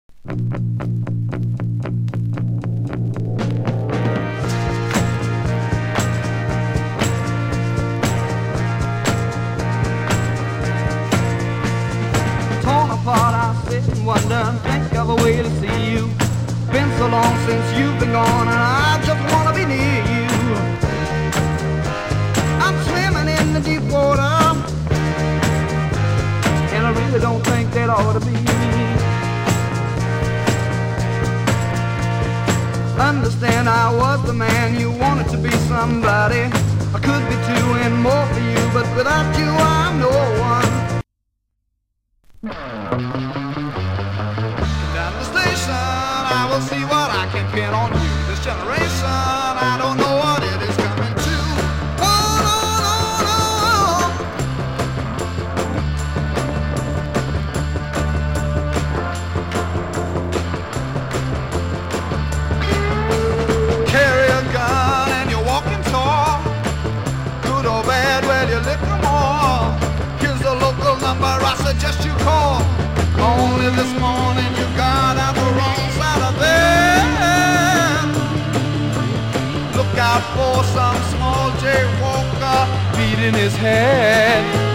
(税込￥3300)   ヘヴィ化